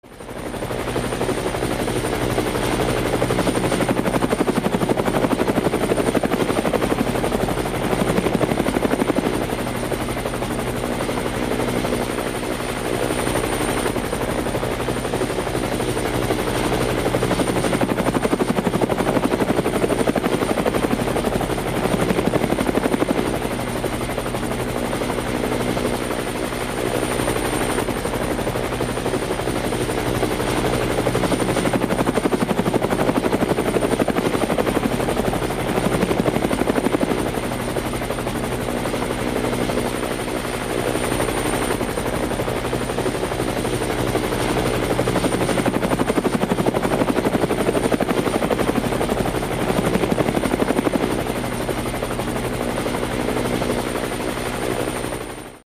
Efectos de sonido
HELICOPTERO
HELICOPTERO es un Tono para tu CELULAR que puedes usar también como efecto de sonido
HELICOPTERO.mp3